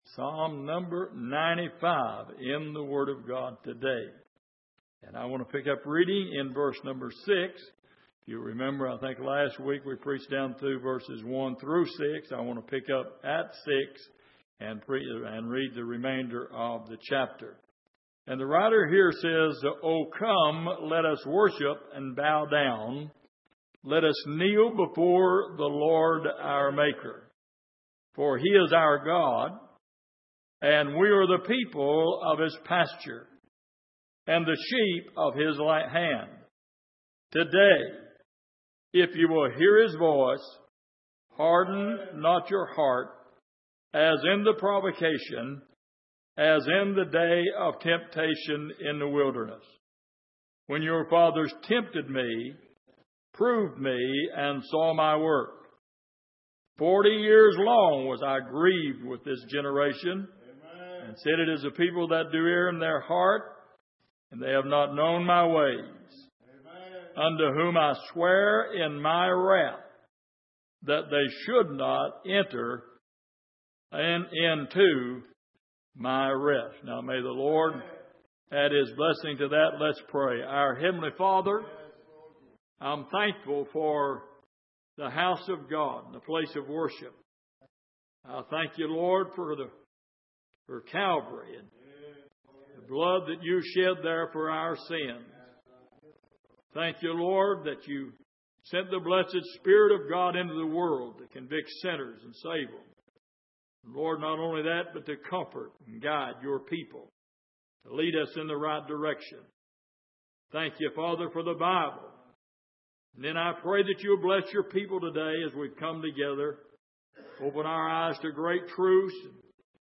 Passage: Psalm 95:6-11 Service: Sunday Morning